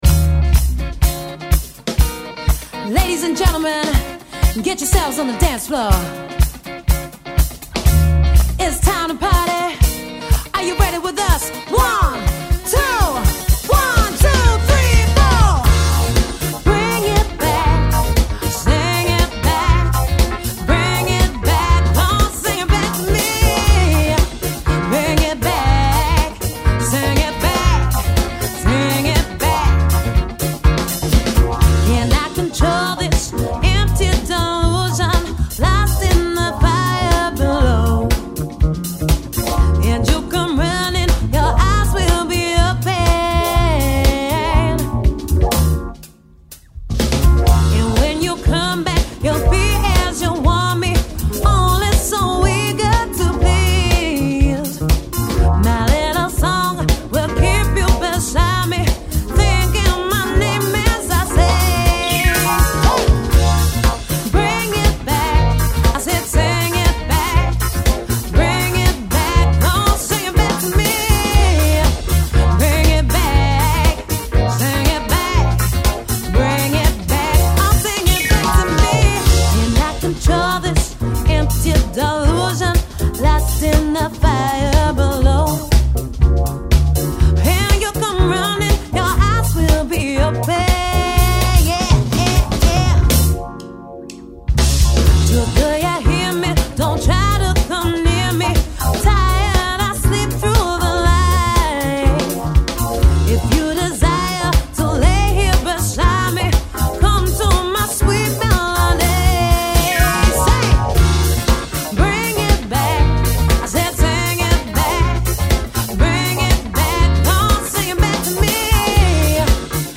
Reviving funk, soul and disco for the 21st century.
(Live)